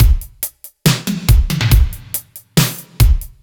Index of /musicradar/french-house-chillout-samples/140bpm/Beats